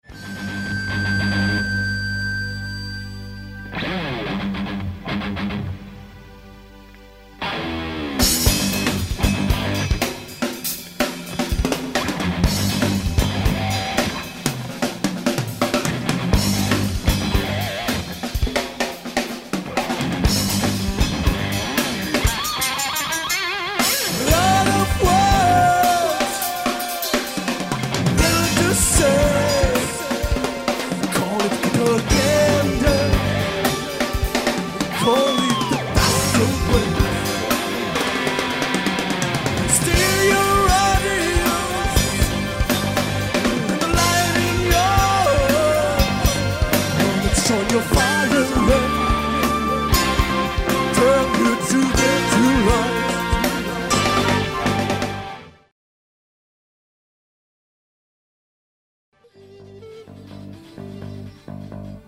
Doppel-Live-Album